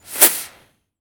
RocketAim.wav